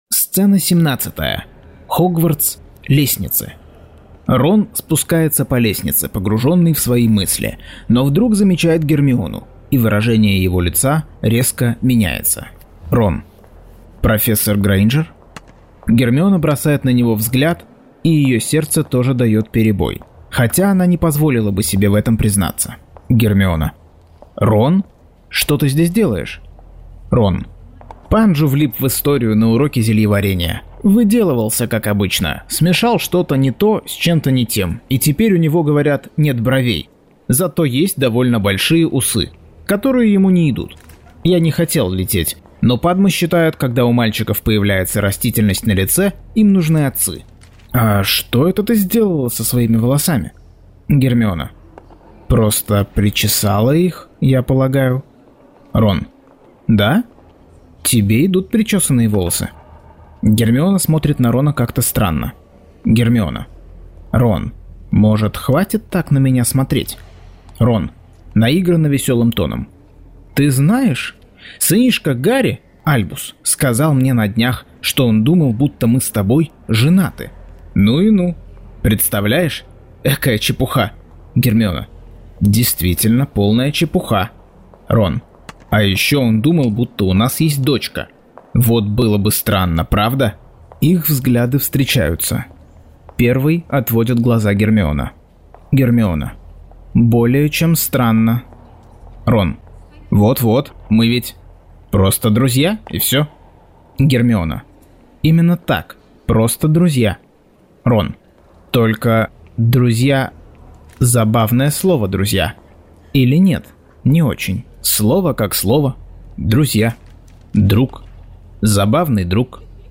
Аудиокнига Гарри Поттер и проклятое дитя. Часть 29.